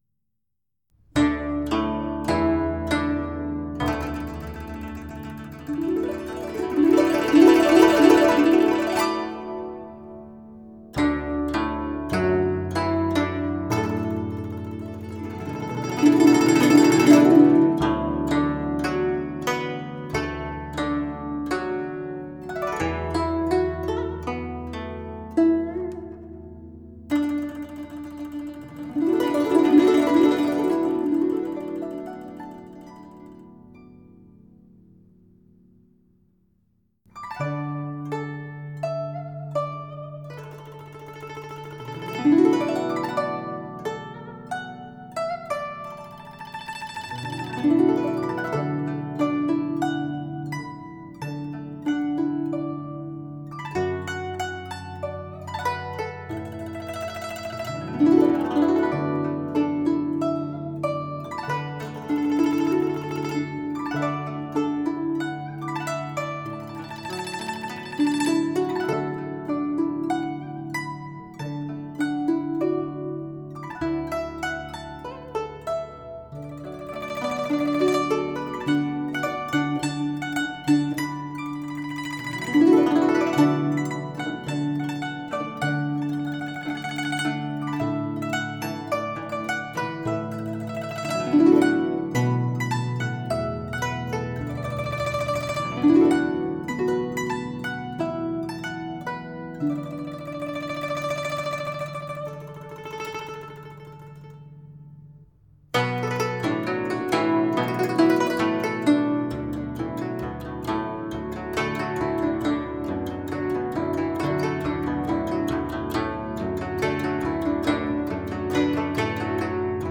乐器演奏系列